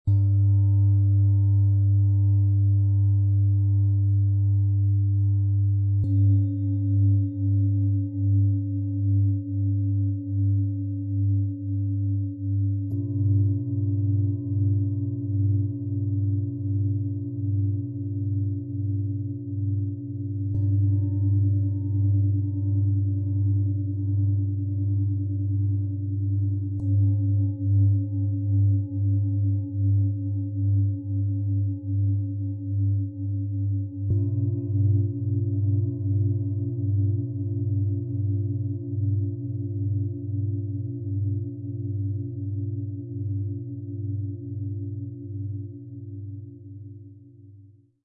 Fundament unten. Bewegung in der Mitte. Klarheit oben - XXL Klangmassage Set aus 3 Klangschalen, Ø 20,8 - 27,6 cm, 4,43 kg
Sie klingen tief und ruhig.
Ihr voller, harmonischer Ton ruht auf einer feinen, ruhigen Bodenvibration.
Ihr heller, freundlicher Ton gibt Struktur.
Die drei Schalen greifen ineinander und schaffen ein geschlossenes, stabiles Klangbild.
Das leichte Pulsieren klingt in der Aufnahme stärker.